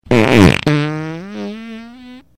R09_0034-bathroom noise
bathroom breaking fart gas noise wind sound effect free sound royalty free Memes